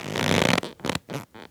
foley_leather_stretch_couch_chair_17.wav